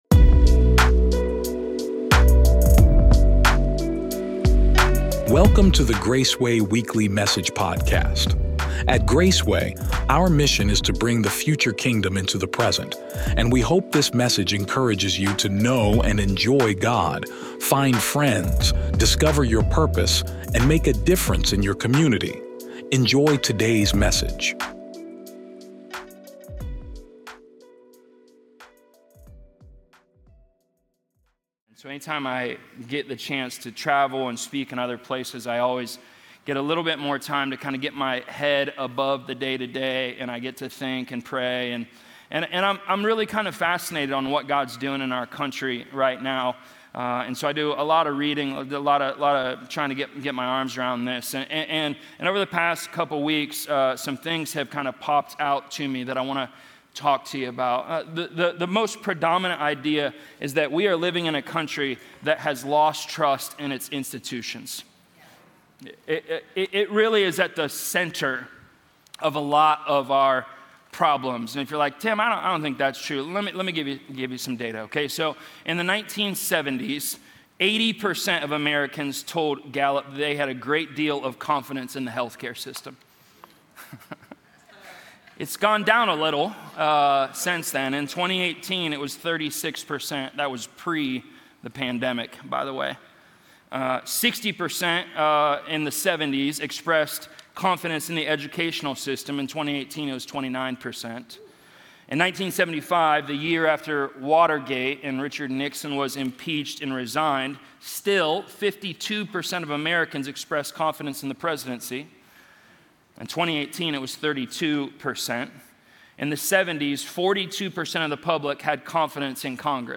Weekend Messages